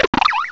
sovereignx/sound/direct_sound_samples/cries/tranquill.aif at 2f4dc1996ca5afdc9a8581b47a81b8aed510c3a8
tranquill.aif